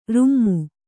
♪ rummu